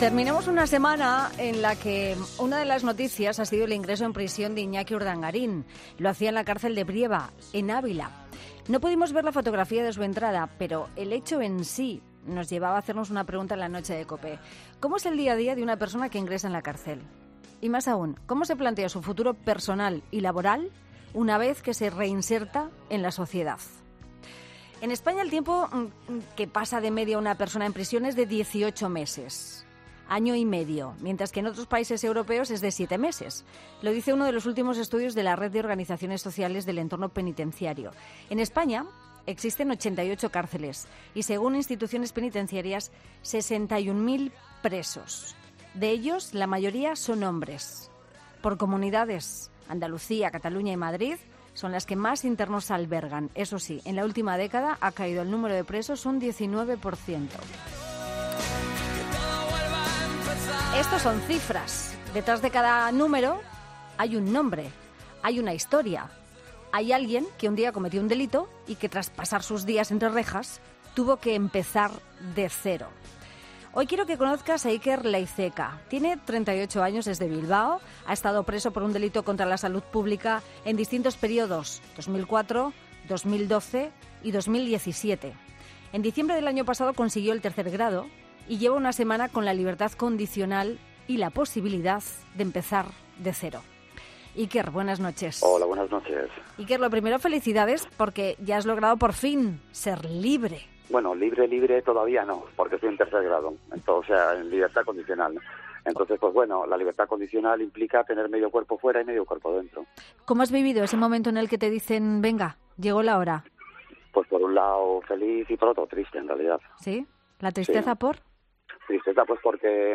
Dos presos comunes que acaban de recuperar la libertad explican cómo se recupera la vida fuera de la cárcel